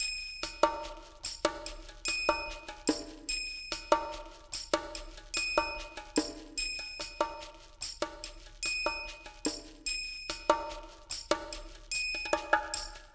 BOL PERCMX-R.wav